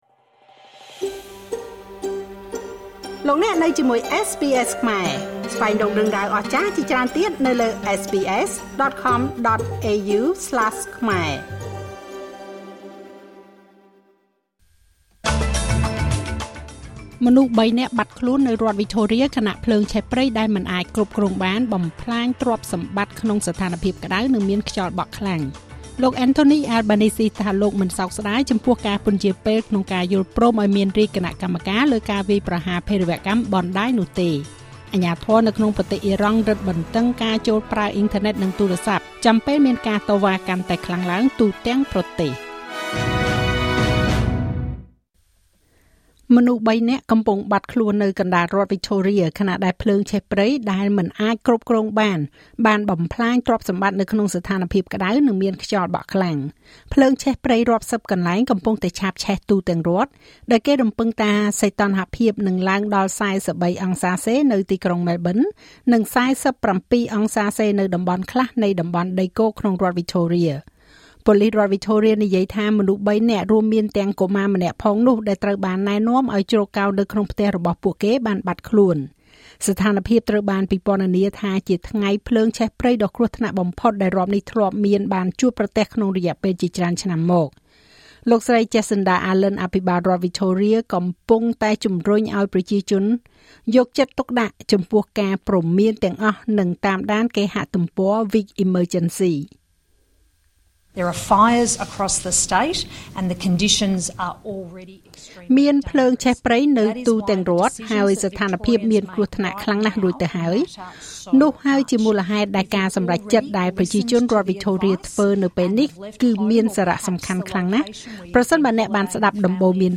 នាទីព័ត៌មានរបស់SBSខ្មែរសម្រាប់ថ្ងៃសុក្រ ទី៩ ខែមករា ឆ្នាំ២០២៦